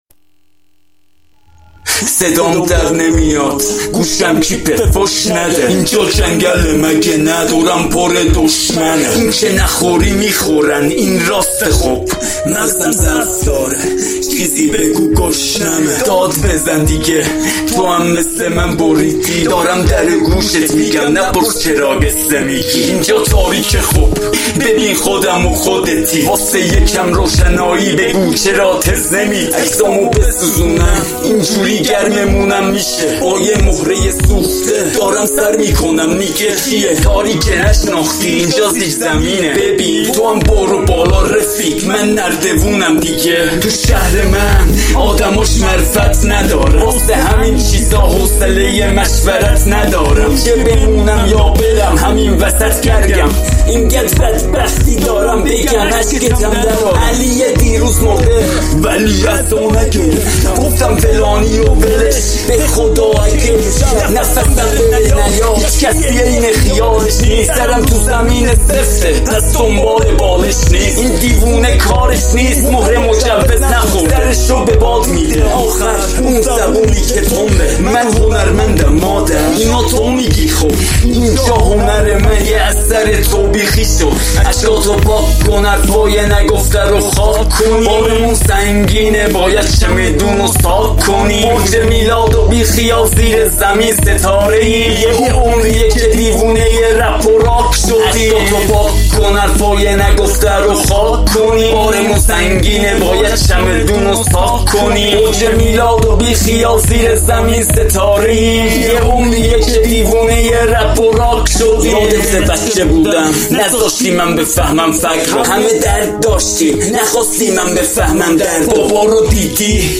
rap&hip hop